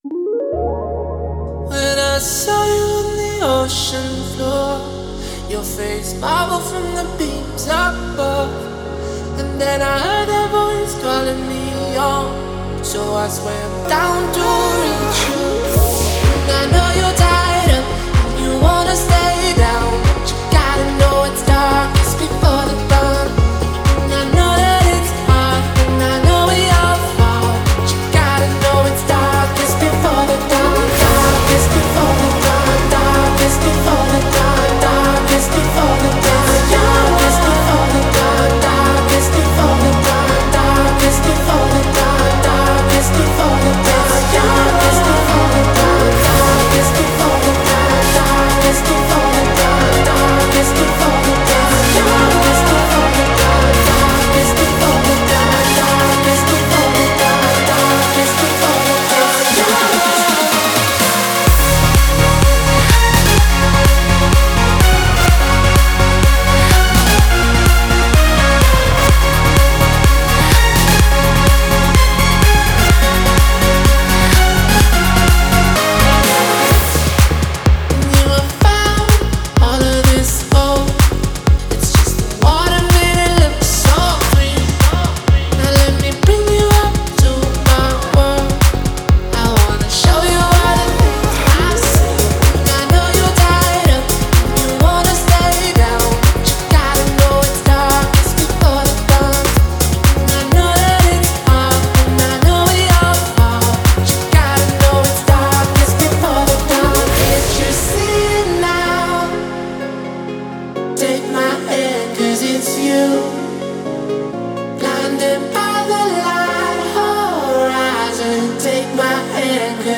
электронная композиция